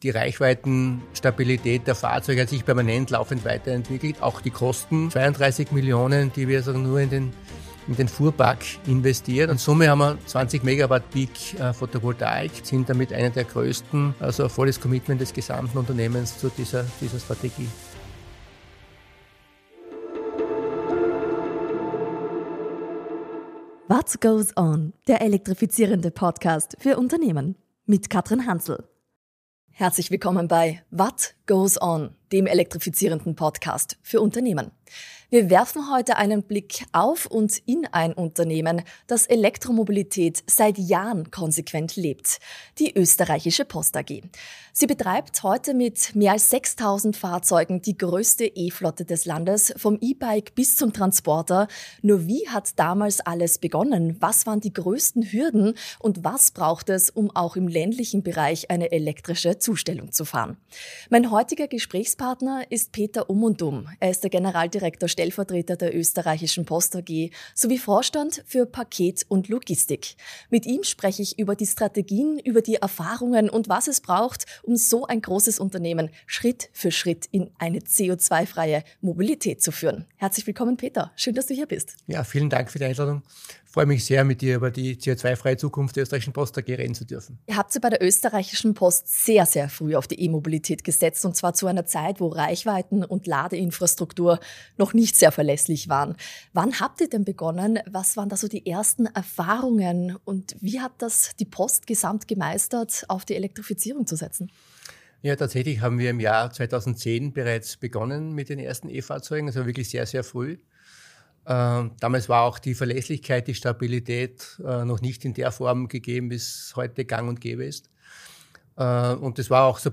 Im Gespräch geht es um Ladeinfrastruktur an Zustellbasen, 20 Megawattpeak Photovoltaik auf eigenen Standorten, intelligente Ladelösungen bis ins Zuhause der Zusteller:innen, erste E-LKWs im Einsatz sowie neue Ansätze wie Vehicle to Grid.